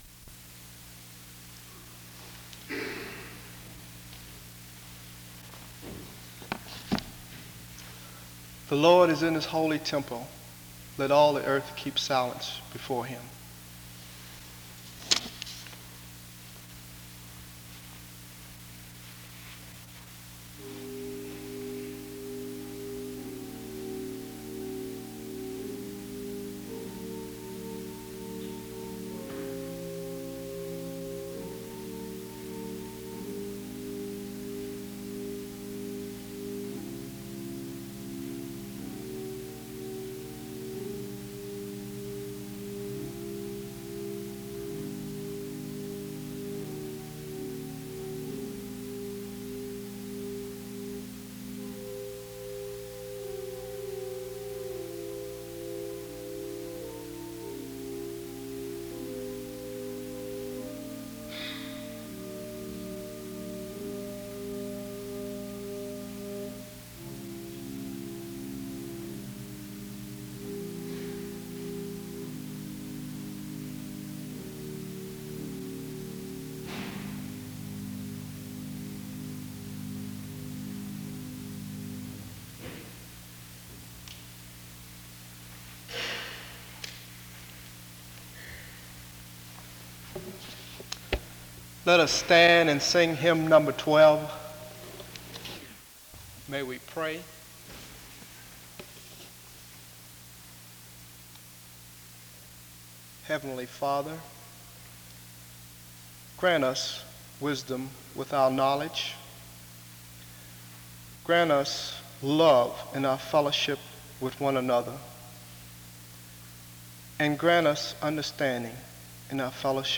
Download .mp3 Description The service opens with the reading of Habakkuk 2:20 (00:00-00:13), followed by a period of silence and instrumental music (00:14-01:31) and the mention of a time of worship from hymn #312 (01:32-01:35).
The chapel anthem is sung (03:30-05:18).
He sums the text up by saying that the divine intrusion and the gospel itself is not found in words, but in the person of Christ (17:16-26:31). He closes the message with prayer (26:32-27:43).